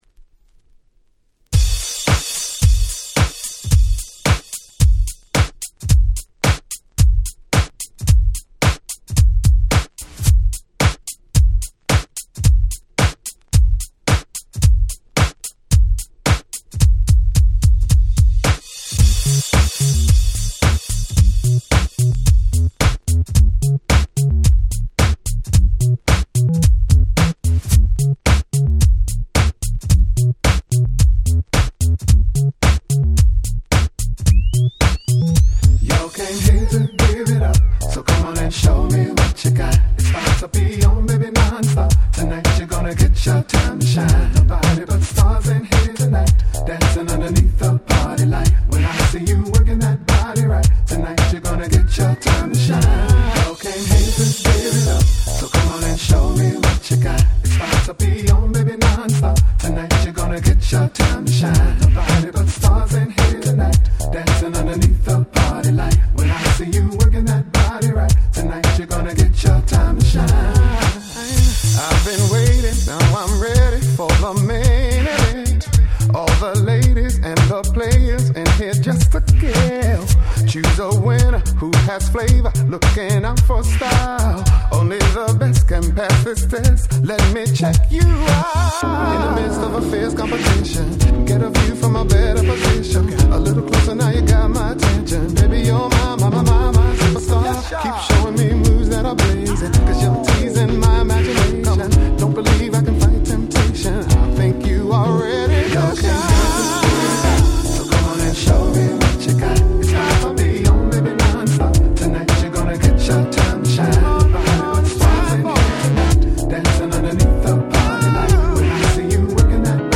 White Press Only Nice Remix !!
06' Smash Hit R&B !!
緩いDisco Beatに彼のVocalでグイグイ引っ張ってくる非常にフロア映えするリミックスに！！